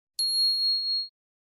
На этой странице собраны звуки, создающие ощущение абсолютной чистоты: от мягкого шума убирающегося помещения до переливов хрустальных нот.